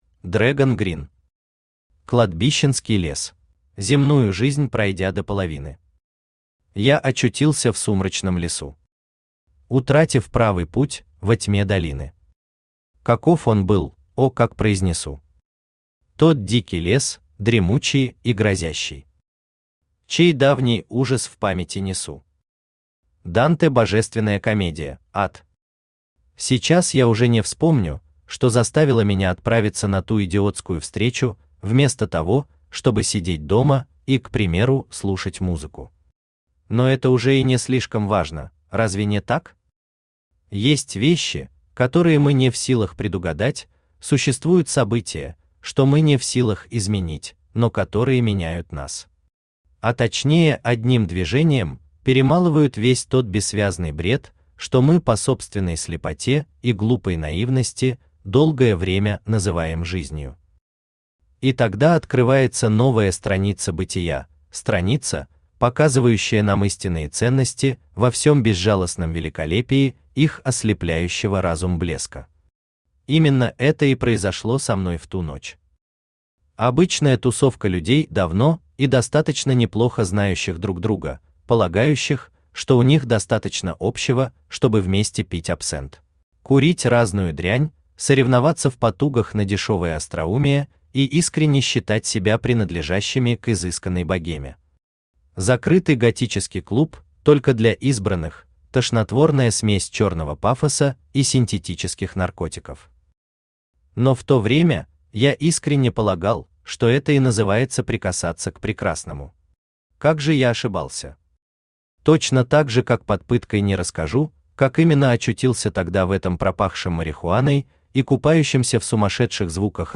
Аудиокнига Кладбищенский лес | Библиотека аудиокниг
Aудиокнига Кладбищенский лес Автор Dragon Green Читает аудиокнигу Авточтец ЛитРес.